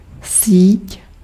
Ääntäminen
IPA: [fi.lɛ]